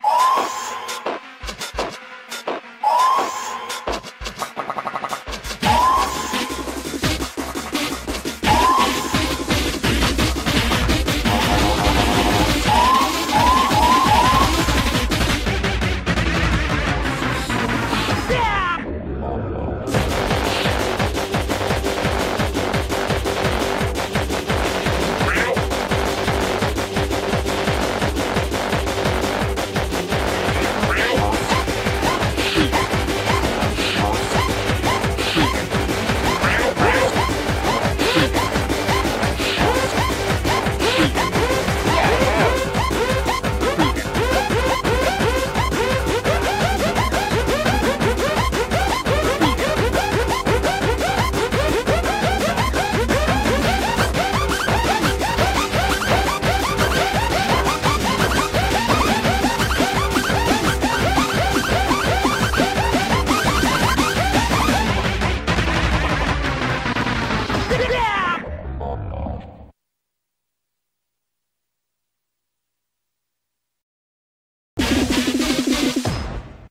BPM135-195
Audio QualityMusic Cut